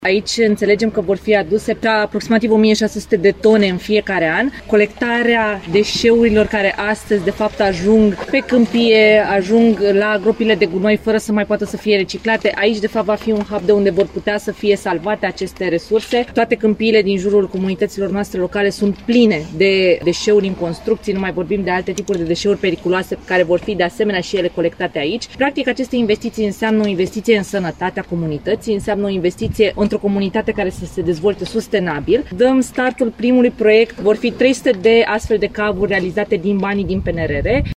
Prezentă la inaugurare, Diana Buzoianu, ministrul Mediului, spune că este o investiție în sănătatea comunității.